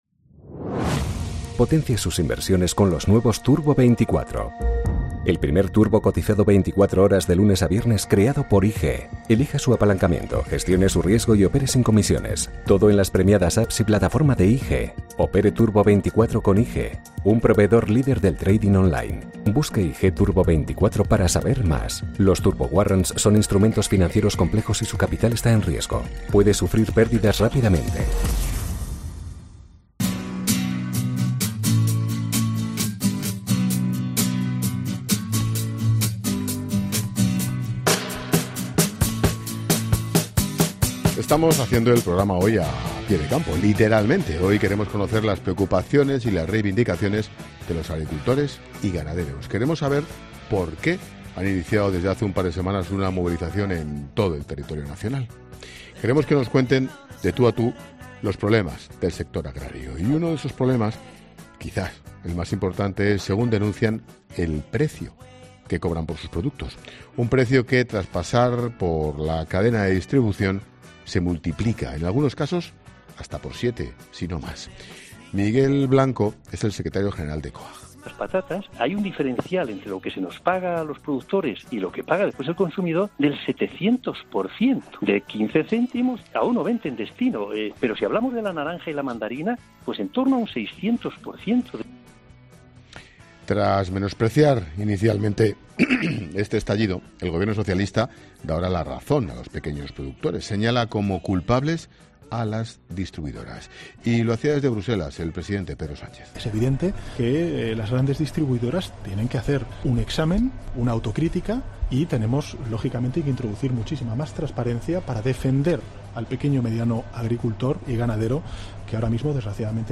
Redacción digital Madrid - Publicado el 10 feb 2020, 20:14 - Actualizado 10 feb 2020, 20:18 3 min lectura Facebook Twitter Whatsapp Telegram Enviar por email Copiar enlace Hoy hacemos el programa a pie de campo y hemos querido conocer las preocupaciones y las reivindaciones de los agricultores y ganaderos.